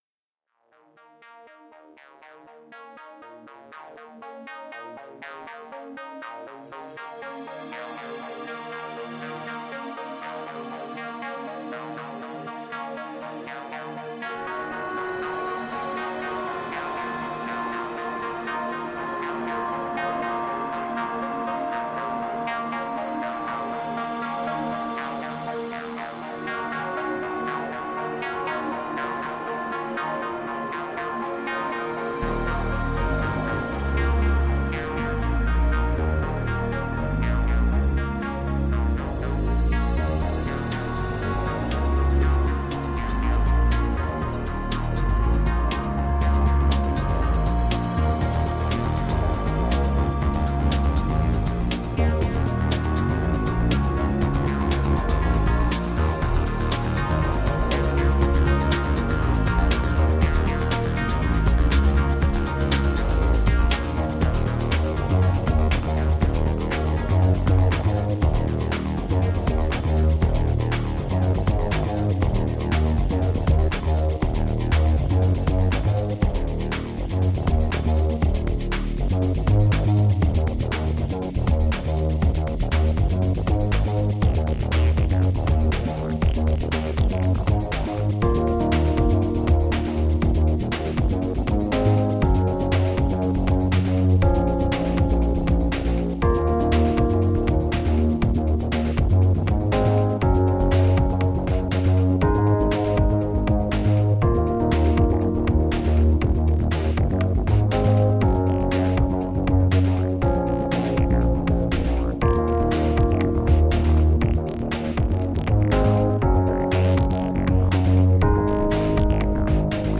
Atmospheric downtempo.
Tagged as: Ambient, Electronica, IDM, Space Music